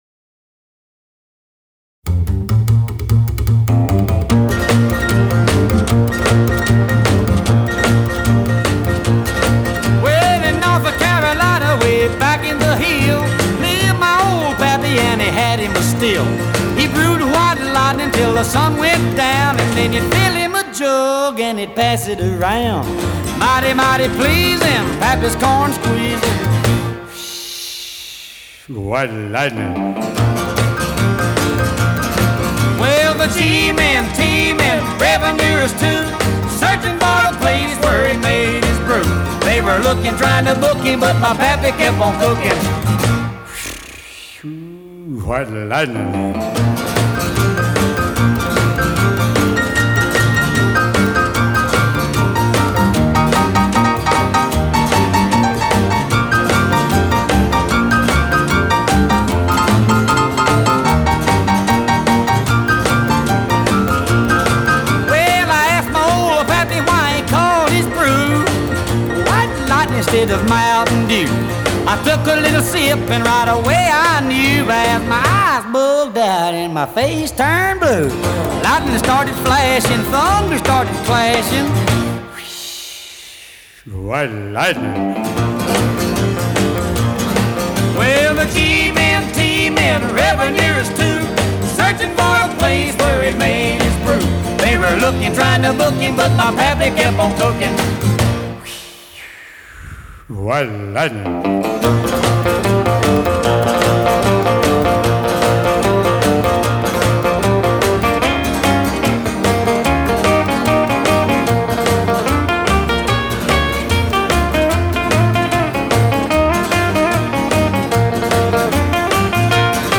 Style: Oldies 50's - 60's / Rock' n' Roll / Country